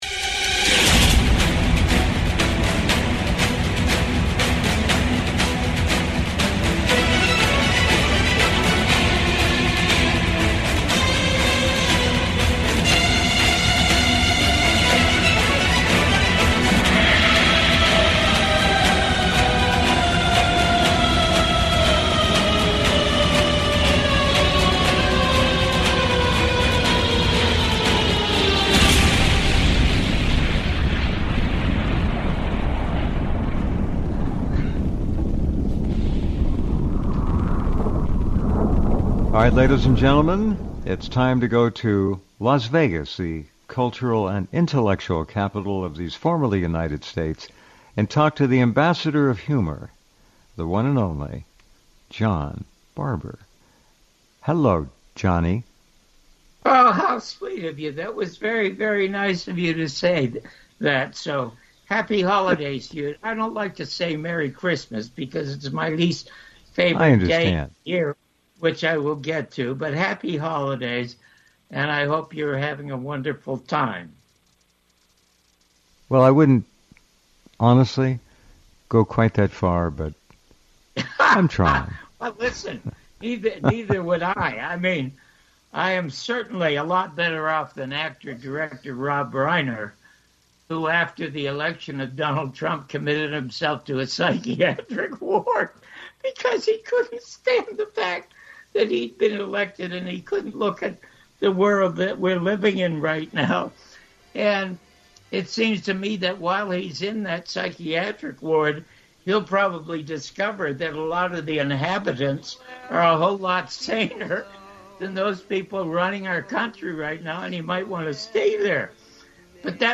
It is my post Christmas little gift of original comedy and comment to all of you, hoping you enjoy listening to it as much as I enjoyed doing it.